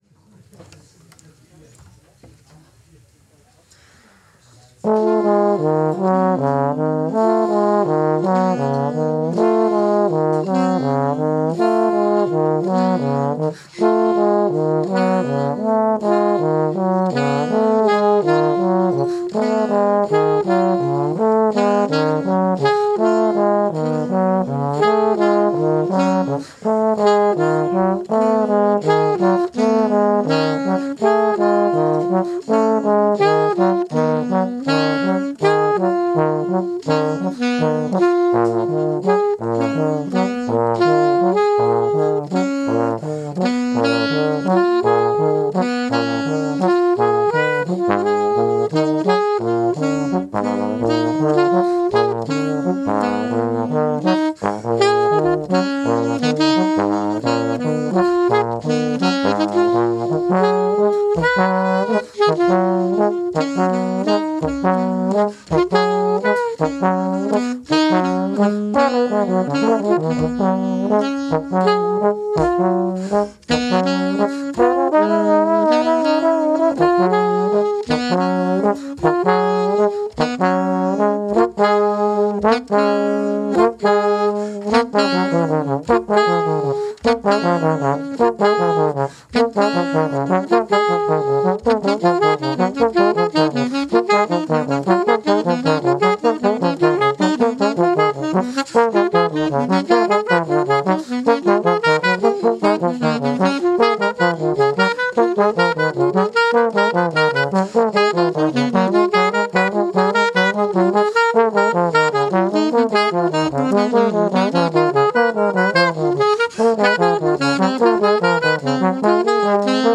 TEIL 2 vom Live-Podcast aus dem Hidèn Harlekin. Dieses Mal sind die Bögen etwas kürzer und wir erzählen davon, wie unsere Musik entsteht. Aufgenommen am 03.09.2025 im Hidèn Harlekin in Zug.